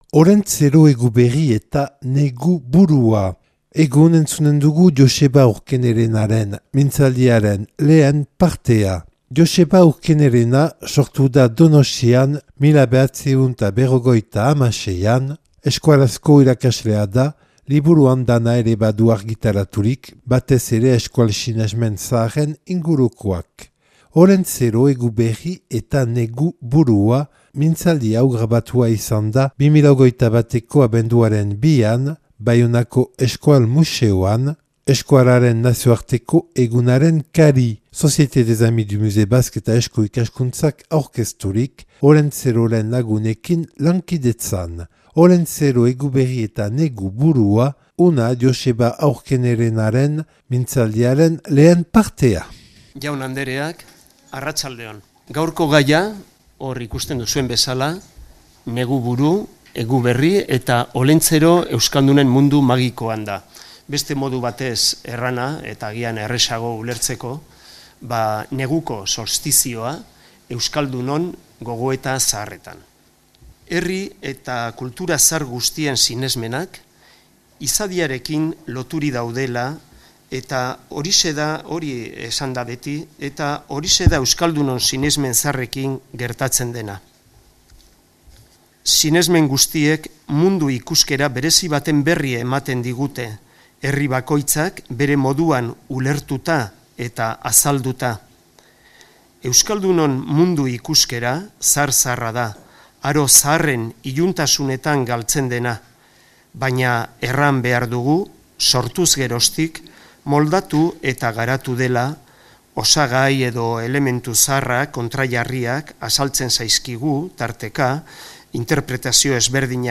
(Baionako Euskal Museoan grabatua 2021.